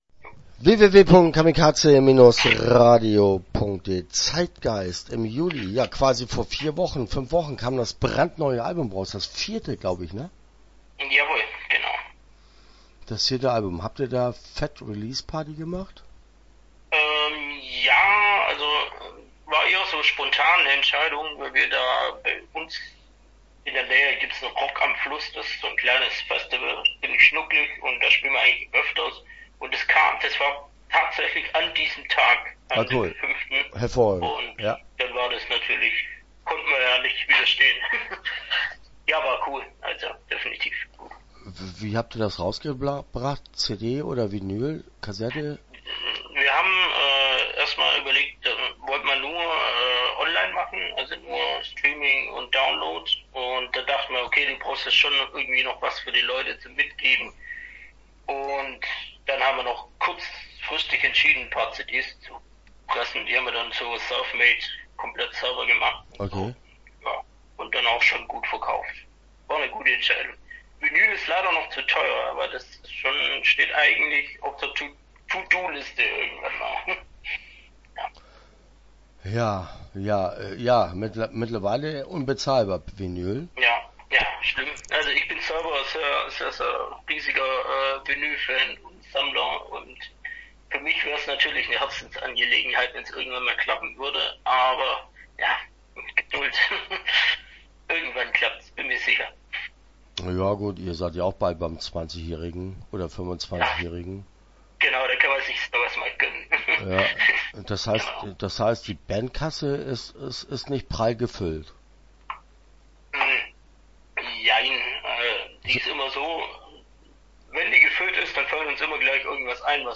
Start » Interviews » Die Kunstbanausen